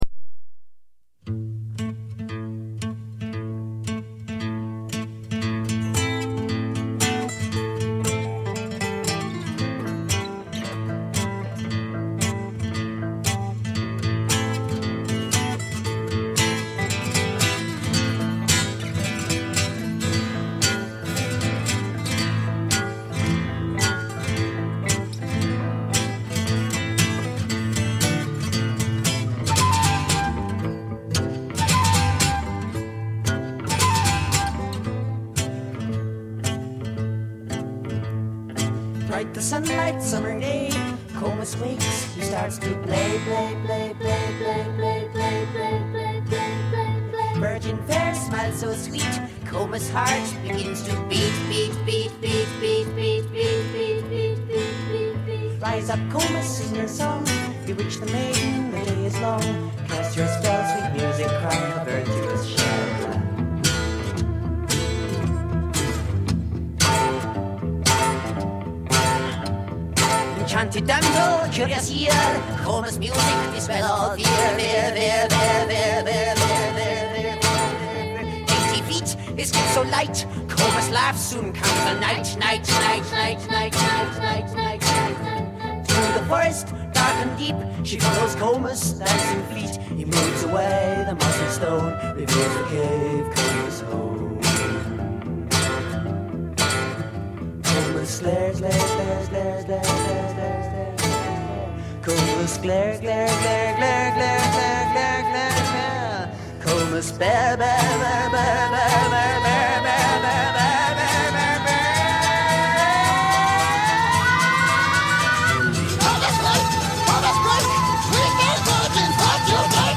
progressive folk band